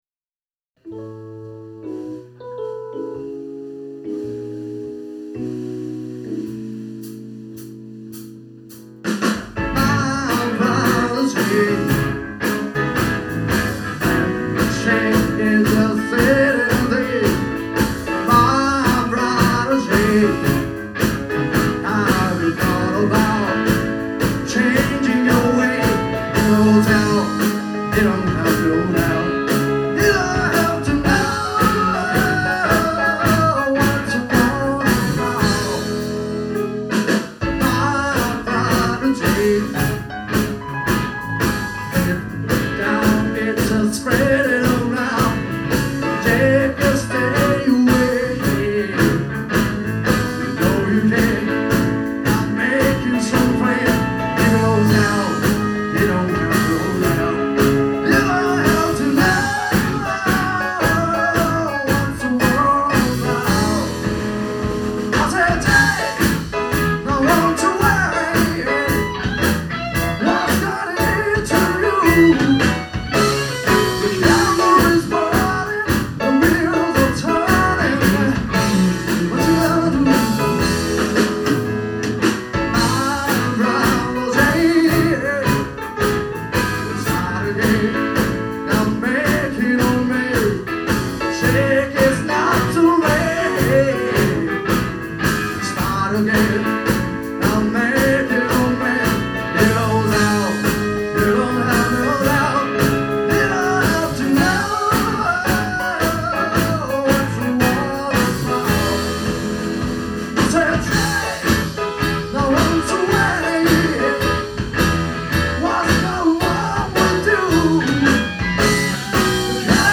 約4年ぶり、久しぶりに原宿クロコダイルでのステージとなりました。